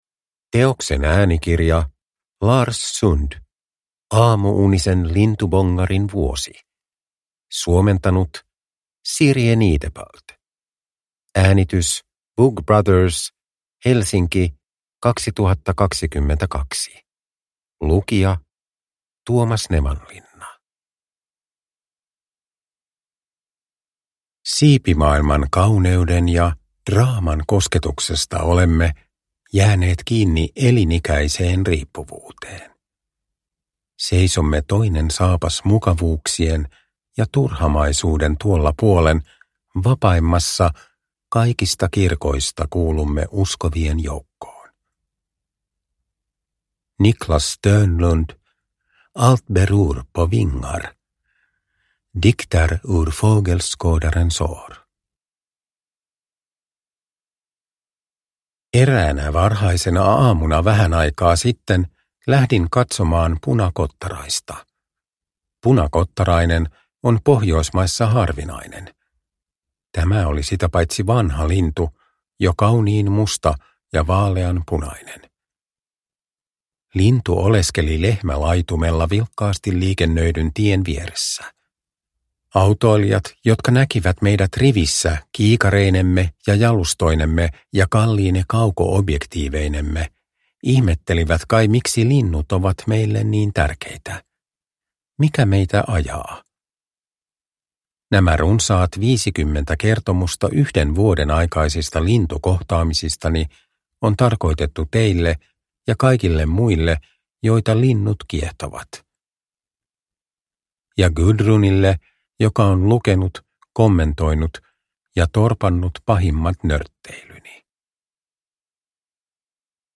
Aamu-unisen lintubongarin vuosi – Ljudbok – Laddas ner